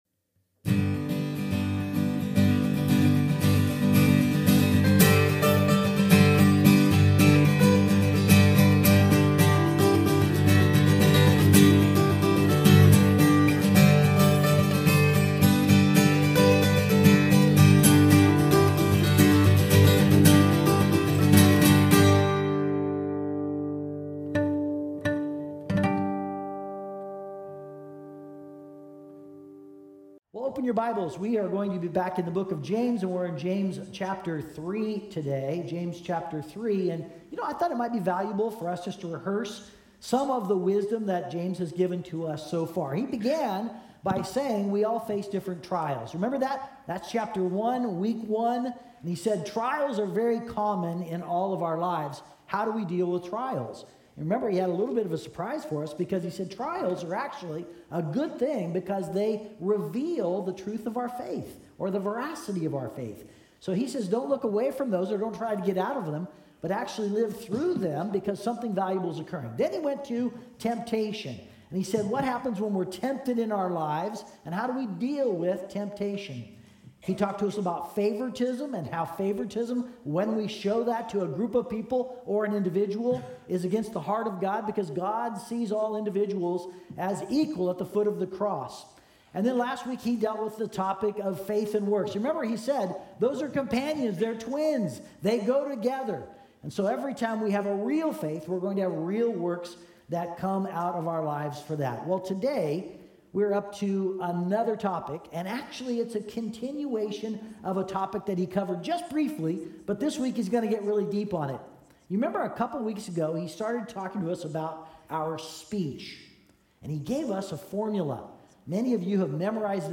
Sermon Podcast from Community Christian Fellowship in Edmonds, WA.